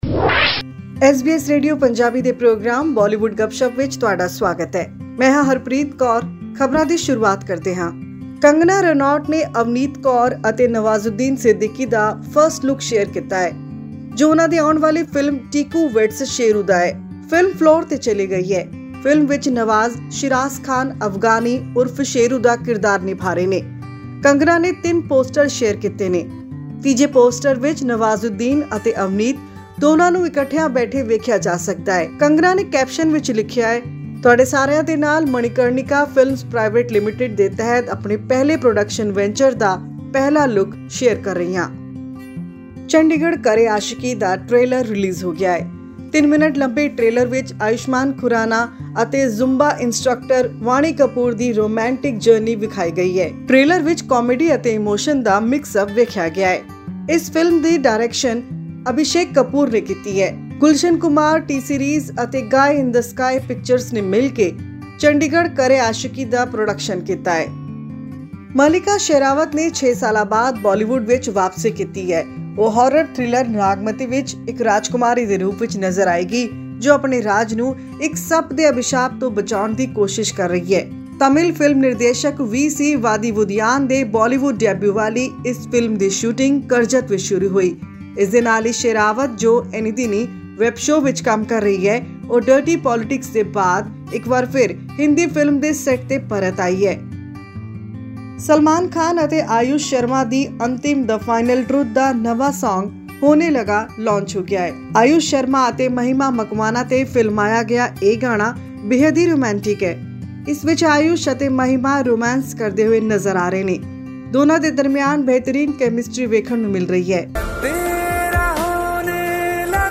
Ayushmann Khurrana and Vaani Kapoor will be seen in a progressive romantic drama 'Chandigarh Kare Aashiqui,' helmed by director Abhishek Kapoor. This and more in our weekly news update from the world of the silver screen.